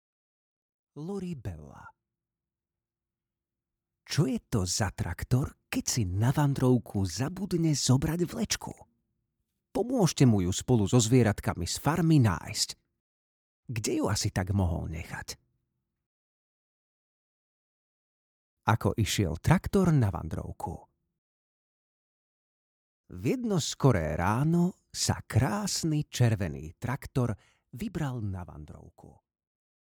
Audio knihaTraktor, ktorý šiel na vandrovku
Ukázka z knihy